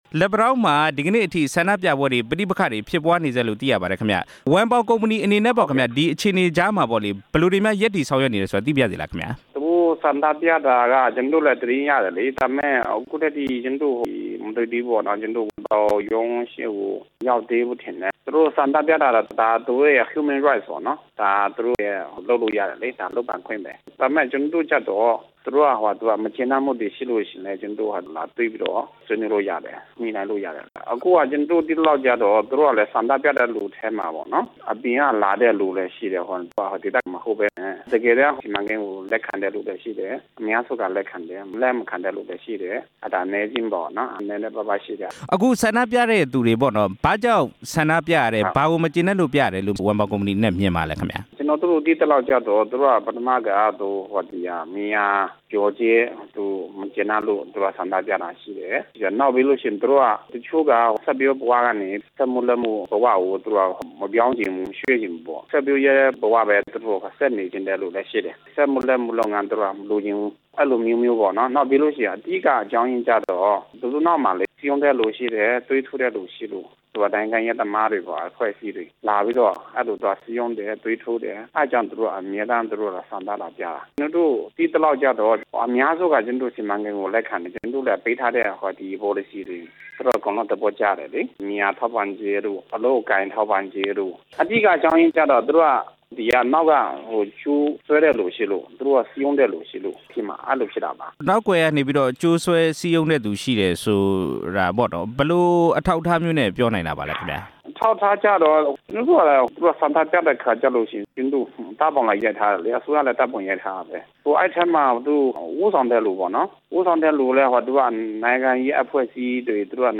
ဝမ်ပေါင်ကုမ္ပဏီနဲ့ RFA သီးသန့်ဆက်သွယ်မေးမြန်းခန်း (ပထမပိုင်း)